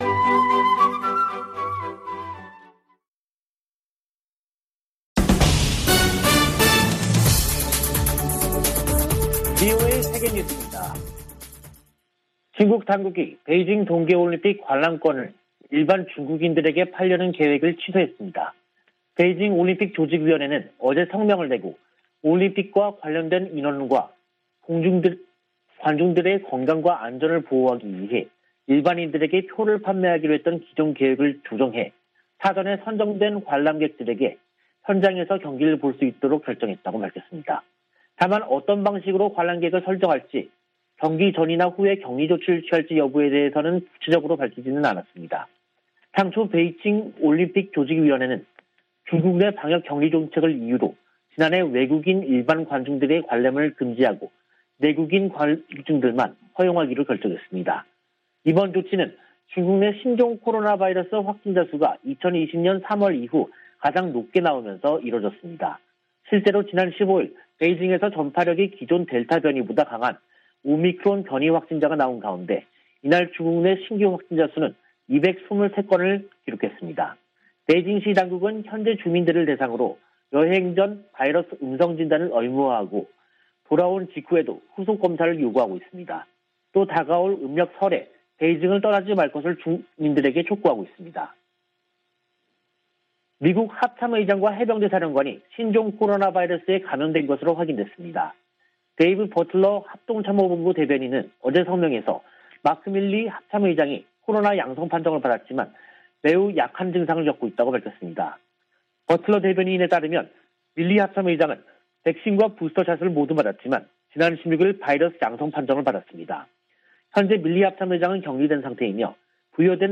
VOA 한국어 간판 뉴스 프로그램 '뉴스 투데이', 2022년 1월 18일 2부 방송입니다. 북한이 17일 쏜 발사체는 '북한판 에이태킴스'인 것으로 파악됐습니다. 성 김 미 국무부 대북특별대표는 북한에 불법 활동을 중단하고 대화에 나서라고 촉구했습니다. 유엔은 북한의 올해 네 번째 미사일 발사에 우려를 표하고, 관련국들이 기존 대화 장치를 활용해 문제 해결에 나설 것을 촉구했습니다.